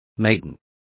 Complete with pronunciation of the translation of maiden.